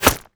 bullet_impact_rock_07.wav